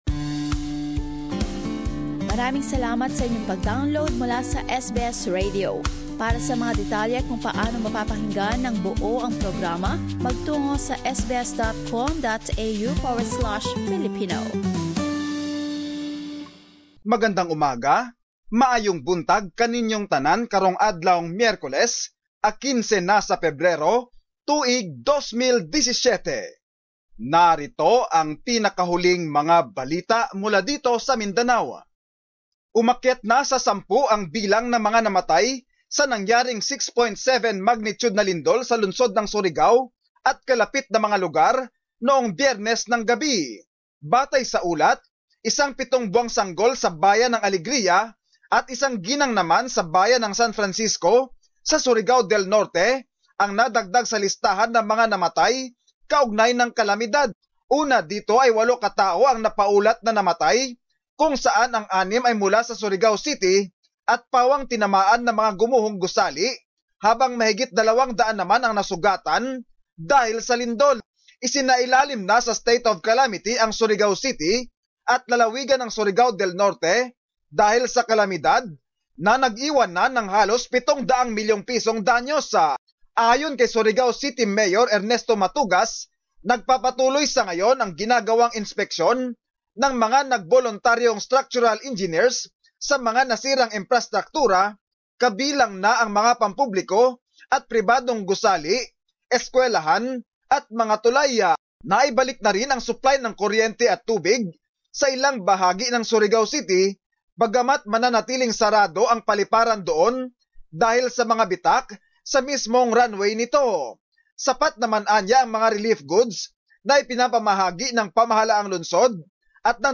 Mindanao News.